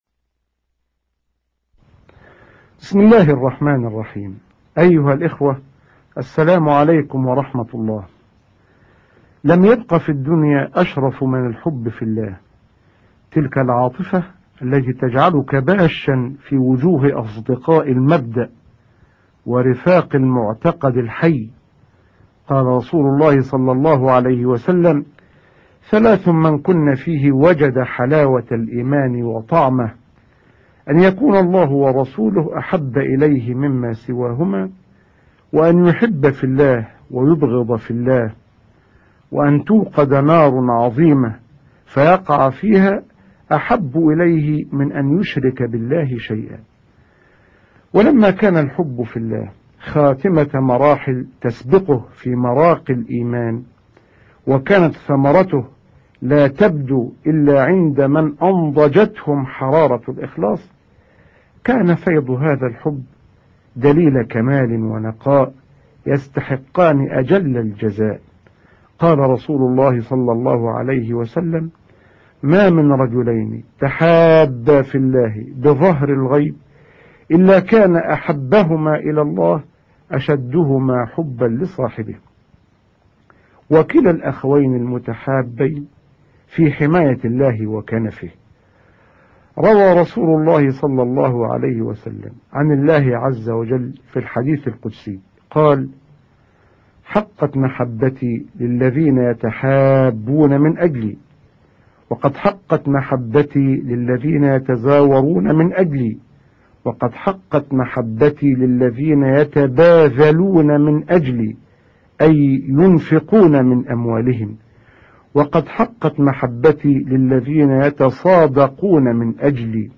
في هذه الصفحة تجدون مجموعة من البرامج الإذاعية التي تنتجها وتقوم بتسجيلها منظمة إذاعات الدول الإسلامية
ادع الى سبيل ربك السعودية اعداد وتقديم الشيخ محمد الغزالي